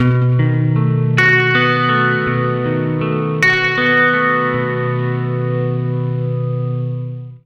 80MAJARP B-R.wav